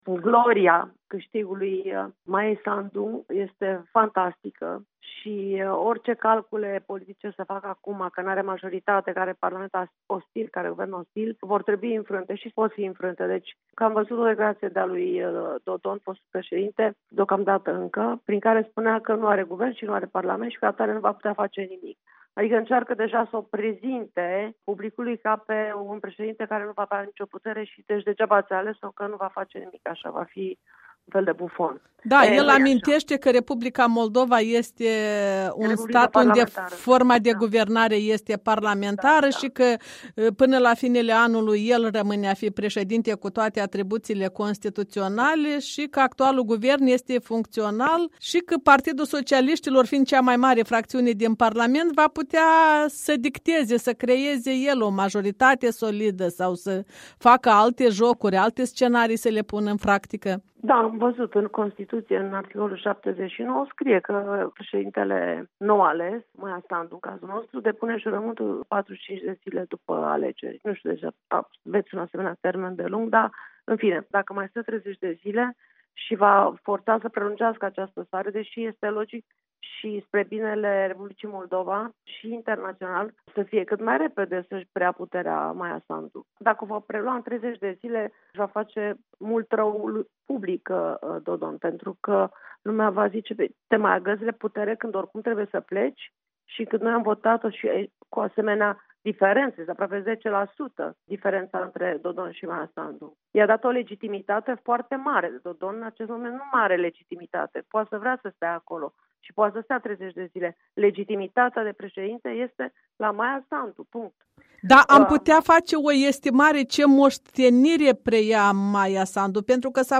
Interviu cu Monica Macovei